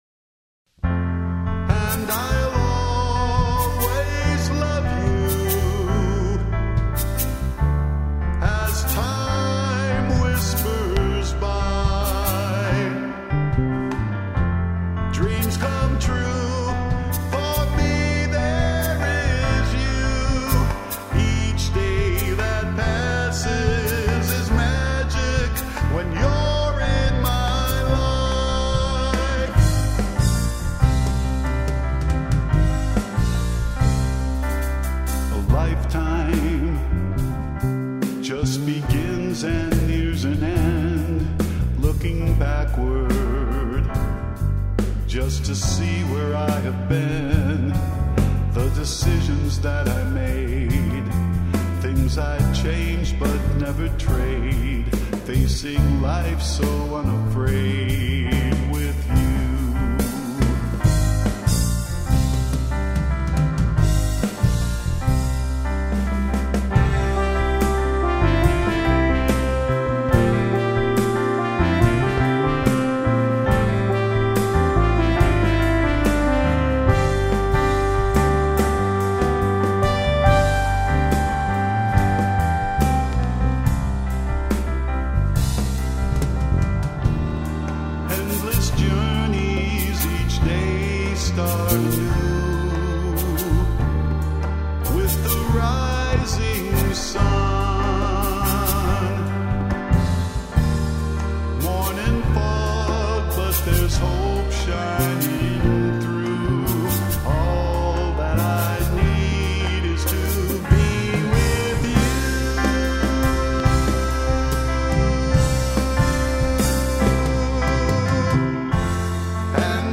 (sep15 remix)
drums
bass